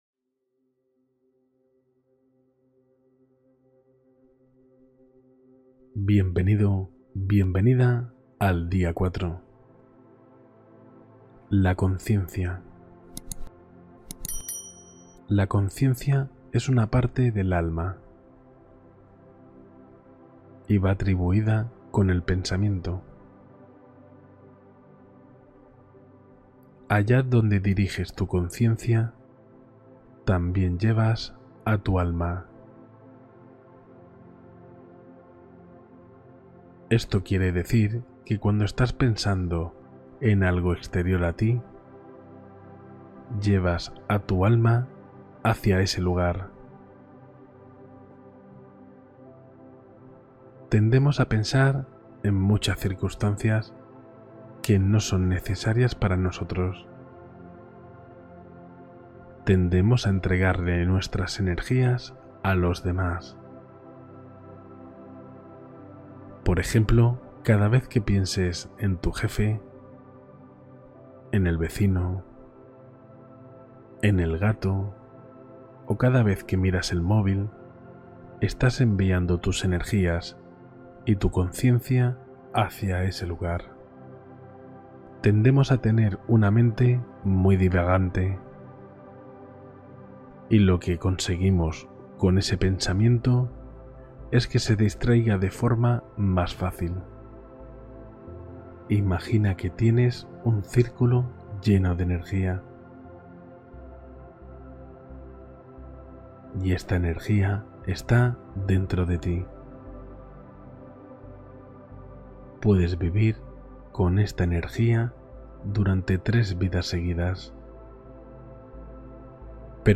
Día 4: meditación para la mente consciente y la reprogramación interior profunda